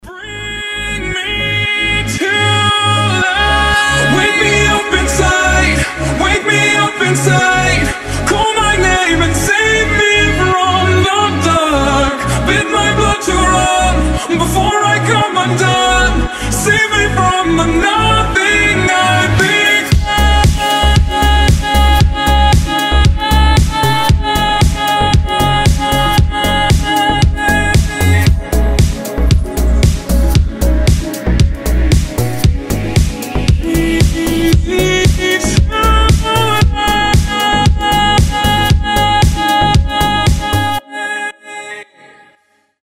• Качество: 320, Stereo
громкие
deep house
грустные
ремиксы